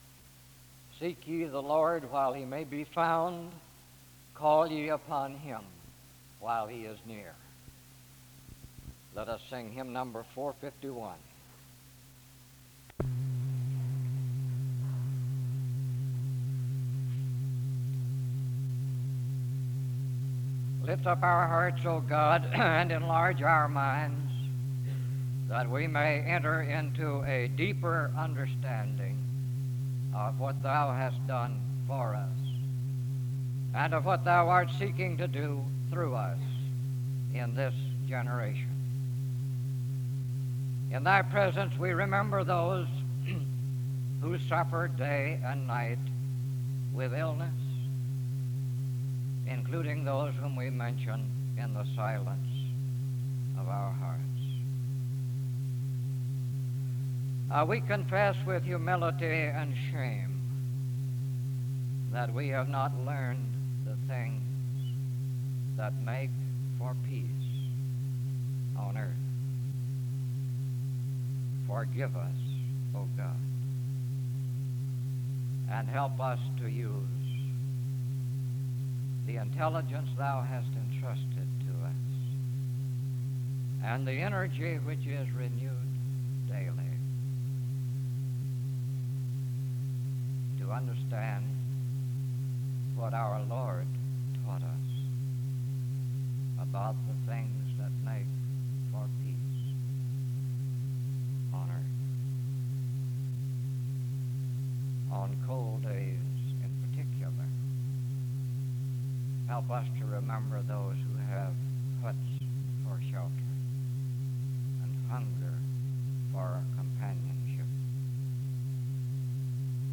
The service begins with a scripture reading and prayer (0:00-2:35). The service continues with a period of singing (2:36-5:05).